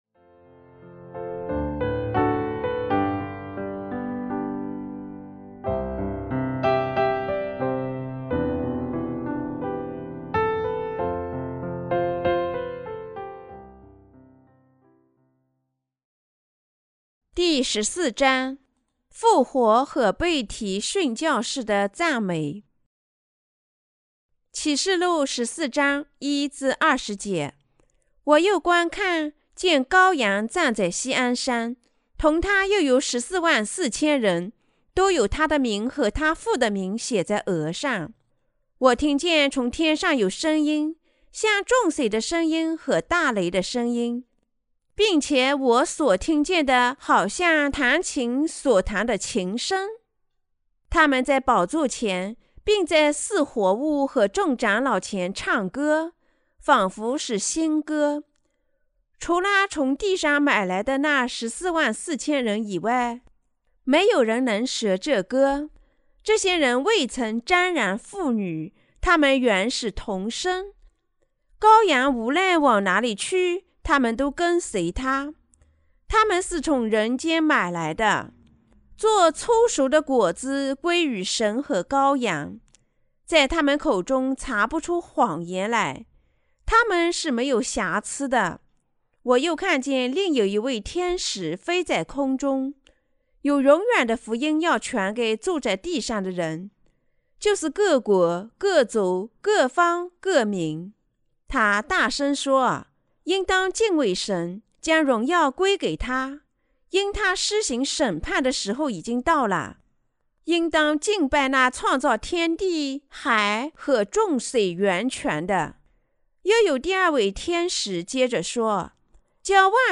關於《啟示錄》的注釋和佈道 - 敵基督者、殉難、被提和千禧年王國的時代來臨了嗎？